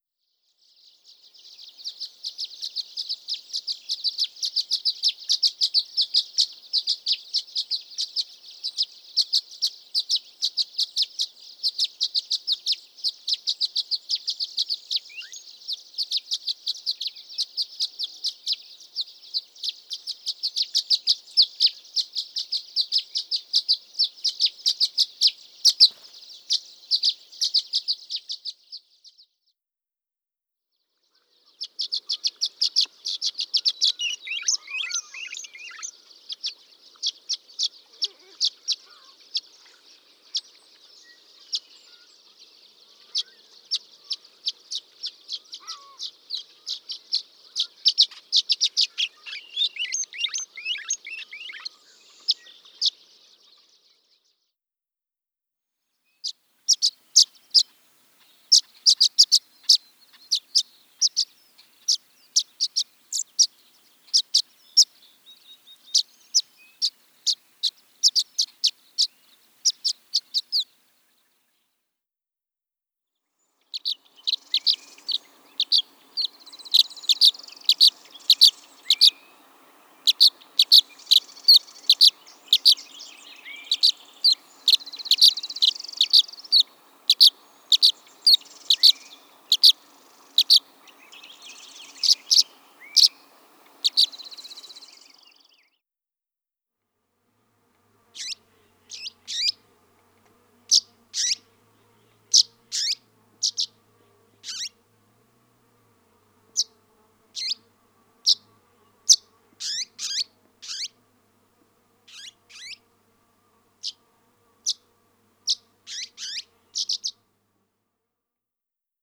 Violet-green Swallow
violet_green_swallow.wav